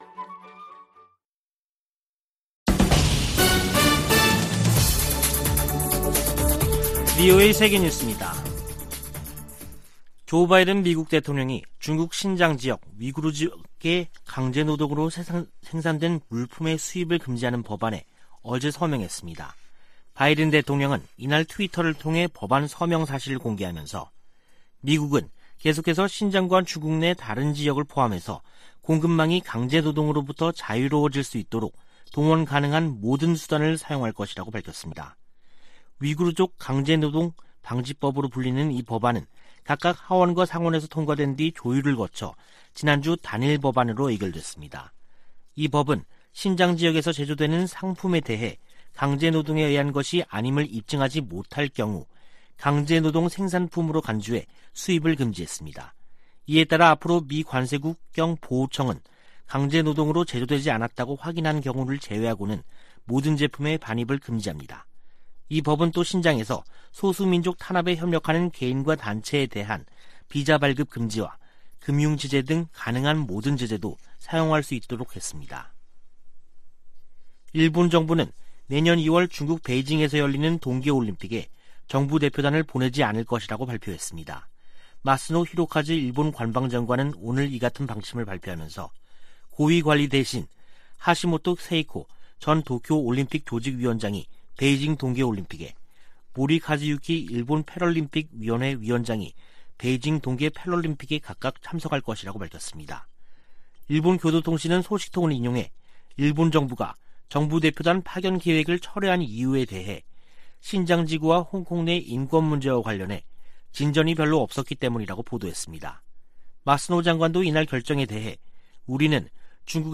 VOA 한국어 간판 뉴스 프로그램 '뉴스 투데이', 2021년 12월 24일 3부 방송입니다. 미국 내 구호단체들은 올 한 해가 대북 지원 사업에 가장 도전적인 해였다고 밝혔습니다. 미 델라웨어 소재 ‘TD 뱅크그룹’이 대북 제재 위반 혐의로 11만5천 달러 벌금에 합의했다고 미 재무부가 밝혔습니다. 한국과 중국이 4년 반 만에 가진 외교차관 전략대화에서 종전선언 등에 협력 방안을 논의했습니다.